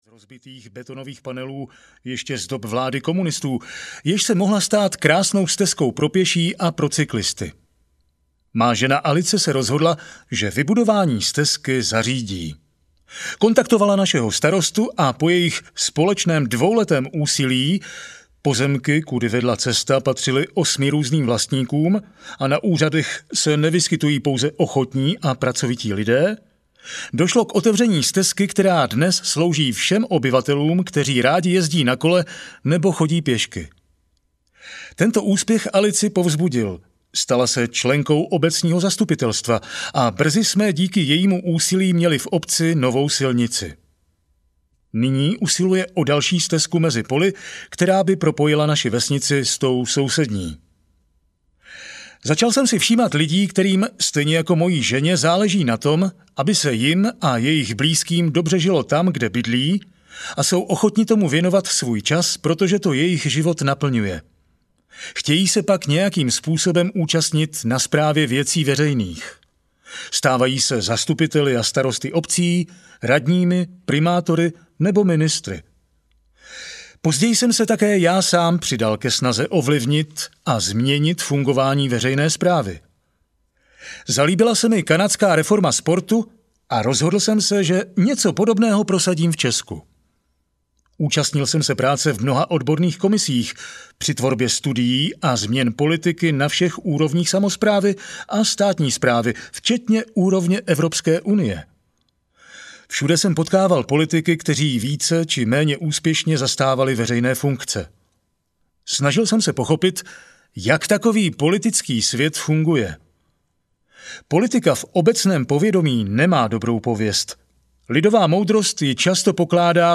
Novela demokracie audiokniha
Ukázka z knihy